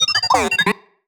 sci-fi_driod_robot_emote_13.wav